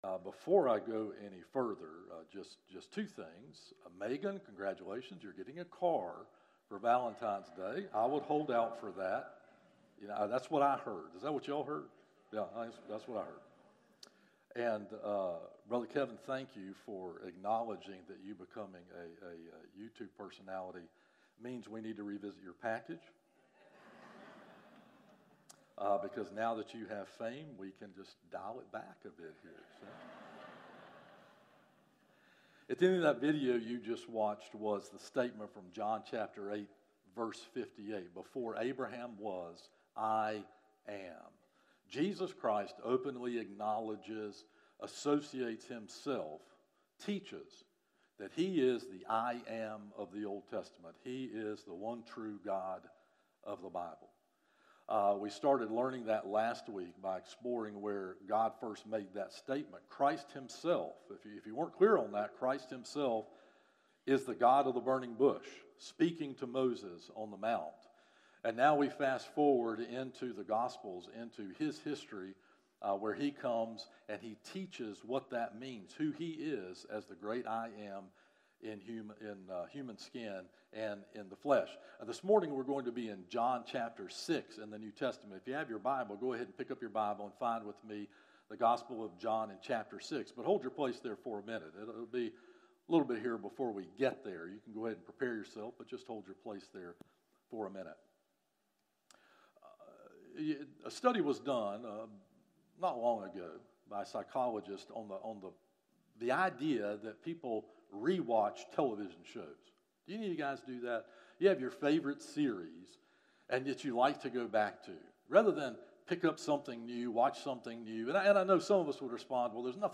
A message from the series "Morning Worship - 11am." I. Jesus Comes From the Father II. Jesus Speaks For the Father III. Jesus Serves Only the Father